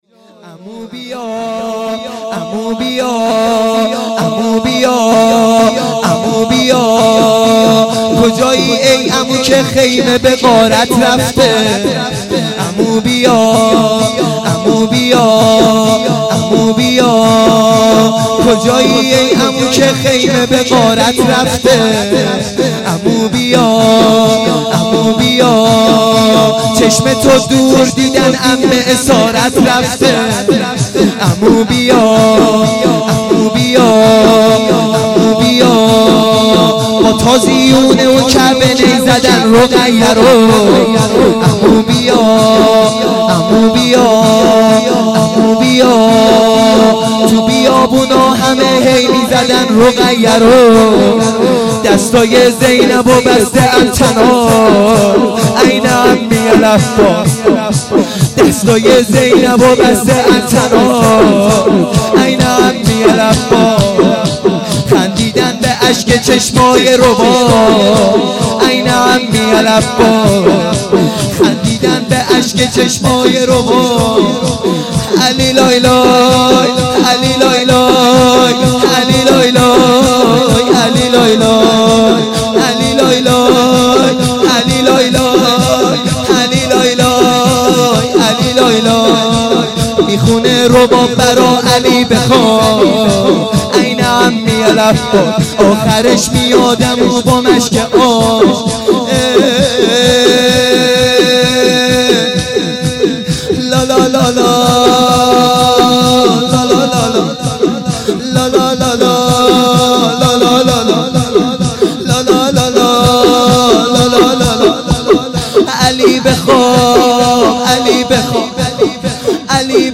لطمه زنی | این عمی العباس
شب چهارم محرم الحرام